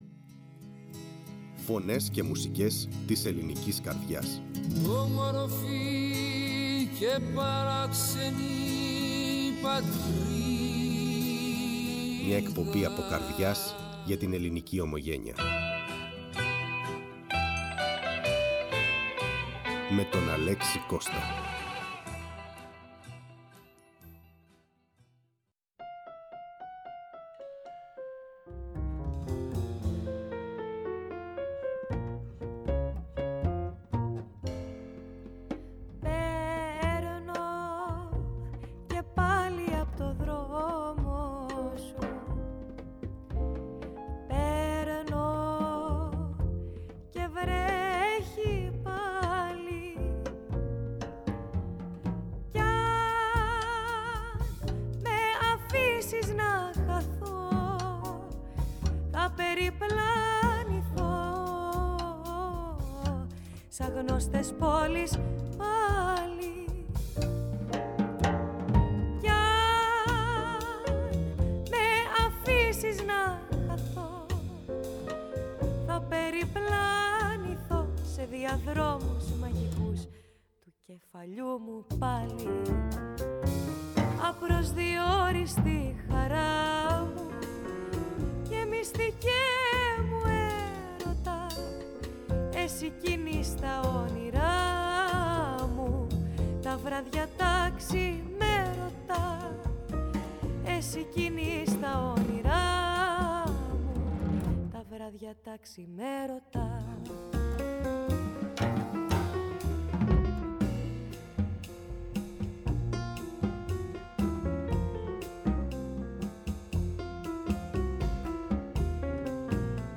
Ο ήχος τους είναι απαλός, ποιητικός και πολύχρωμος.
Συνεντεύξεις